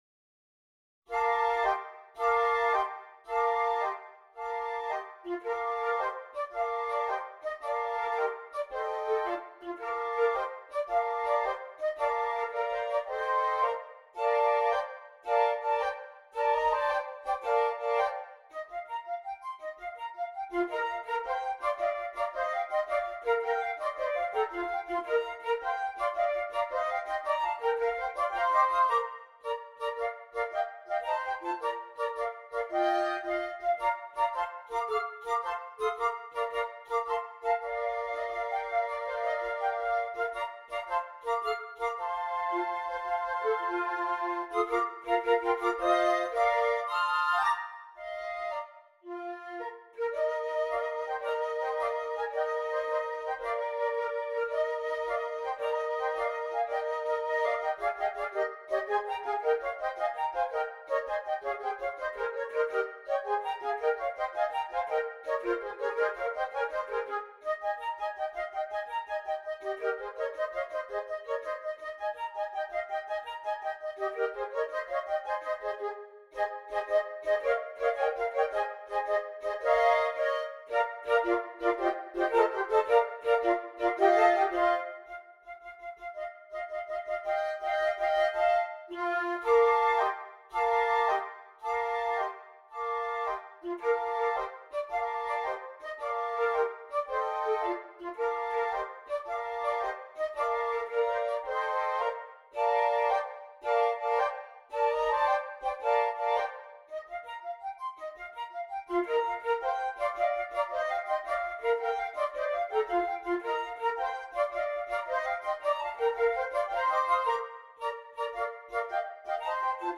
6 Flutes
Traditional Carol